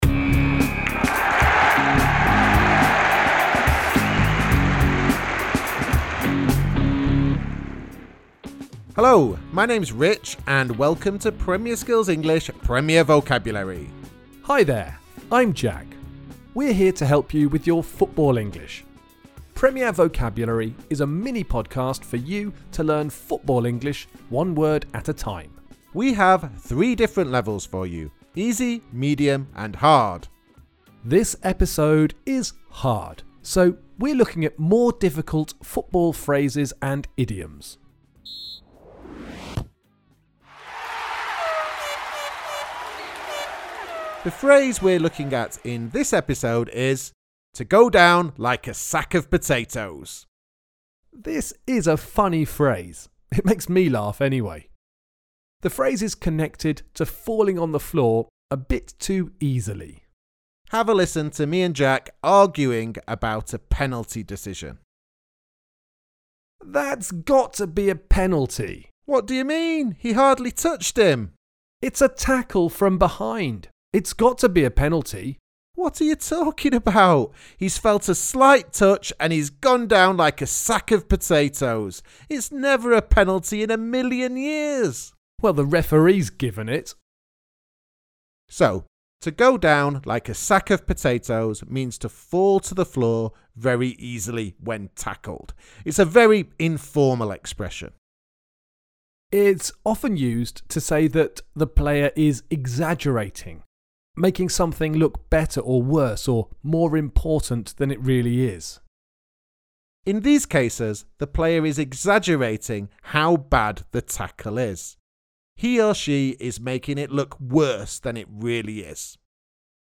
Premier Vocabulary is a mini-podcast for you to learn football English one word at a time.